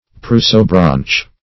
Prosobranch \Pros"o*branch\, n.
prosobranch.mp3